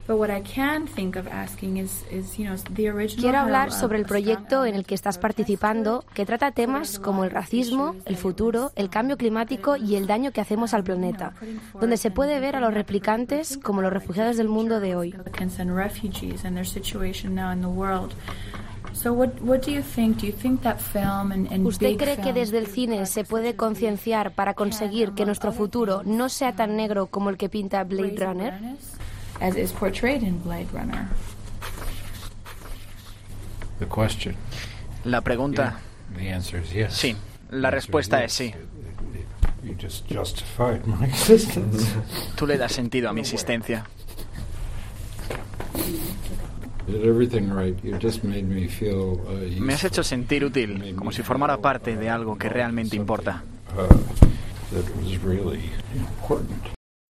En mitad de la entrevista, una periodista le hizo una pregunta al actor estadounidense sobre el futuro de nuestro planeta tras la que Ford no pudo contener la emociónEste era el momento en el que la periodista le hacía la pregunta: